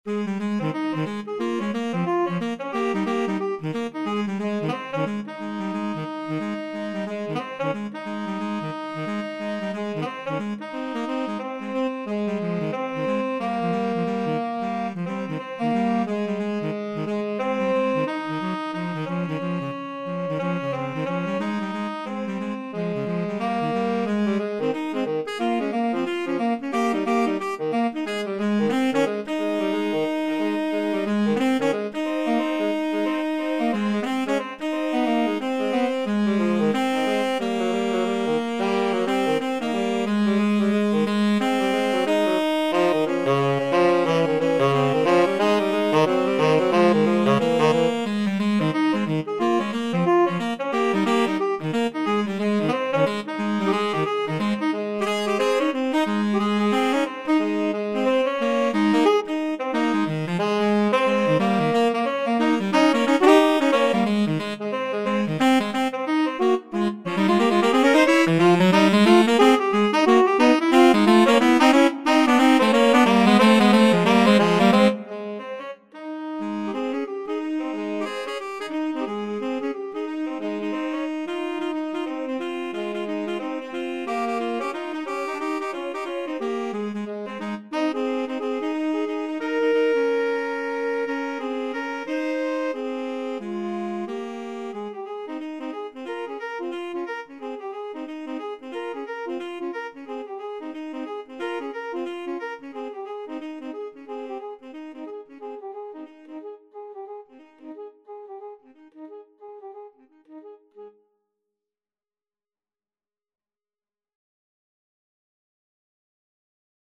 2/2 (View more 2/2 Music)
Fast and with a swing =c.90
Classical (View more Classical Tenor Sax Duet Music)